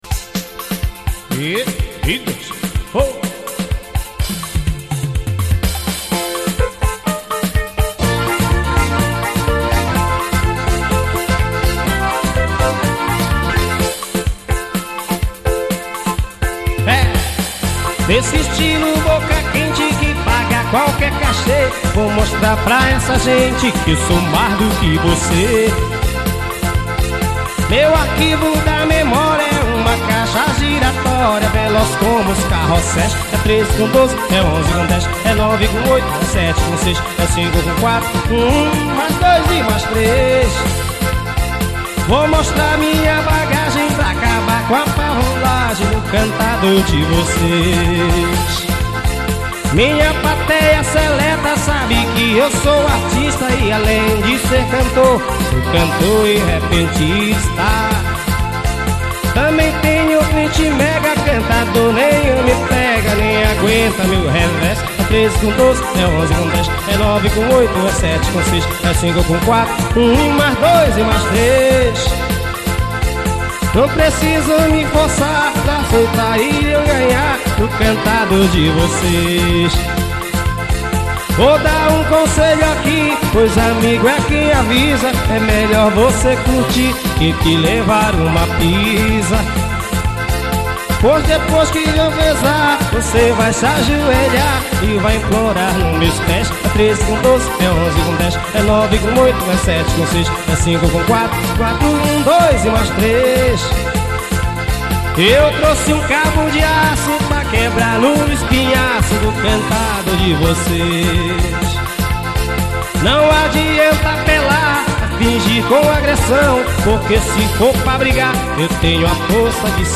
AO VIVO MACAPA -AP 2000.